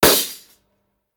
Danza árabe, golpear un pandero 02: golpe sordo
pandereta
pandero